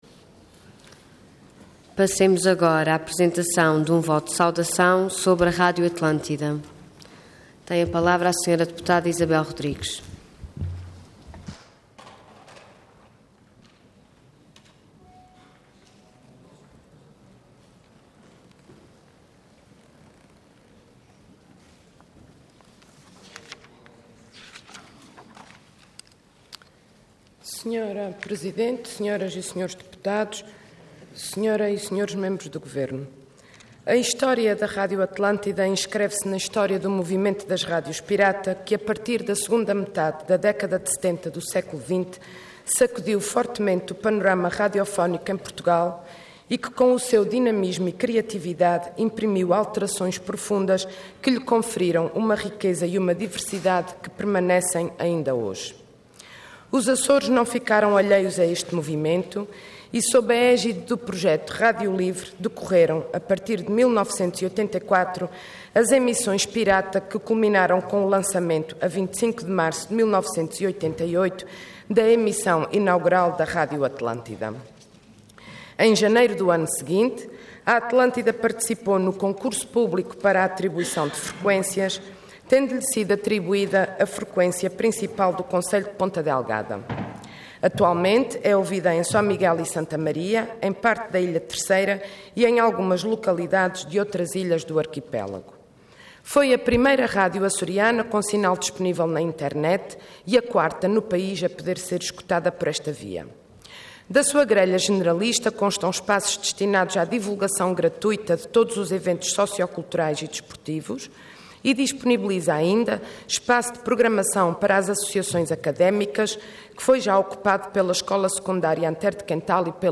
Intervenção Voto de Saudação Orador Isabel Rodrigues Cargo Deputada Entidade PS